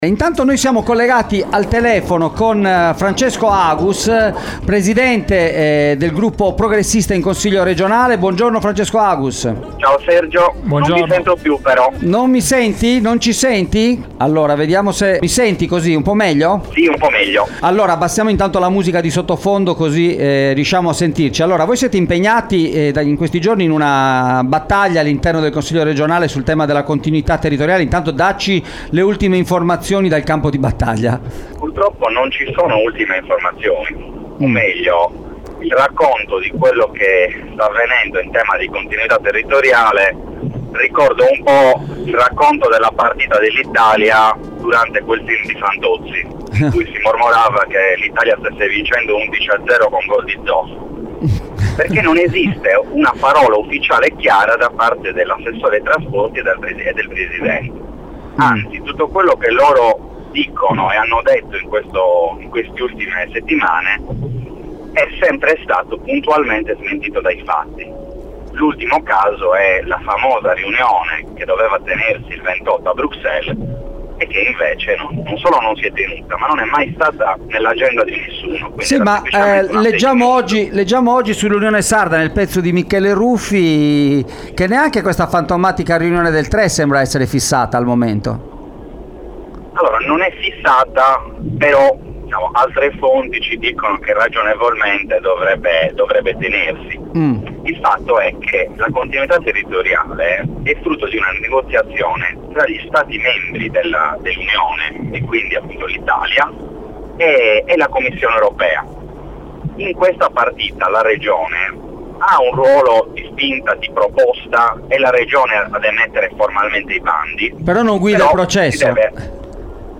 Francesco Agus, presidente del gruppo Progressisti in Consiglio regionale, impegnato in questi giorni nella battaglia sulla continuità territoriale, è intervenuto ai microfoni di Extralive mattina per fare il punto della situazione in corso, che rischia di lasciare a terra migliaia di sardi e complicare le già critiche situazioni del trasporto aereo da e p